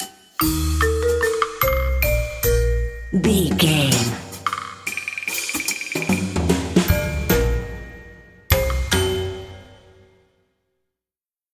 Ionian/Major
B♭
drums
percussion
double bass
silly
circus
goofy
comical
cheerful
perky
Light hearted
secretive
quirky